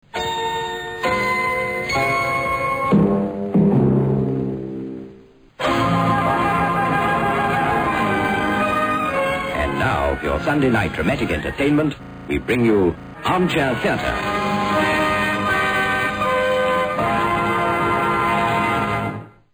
ABC Television Armchair Theatre announcement.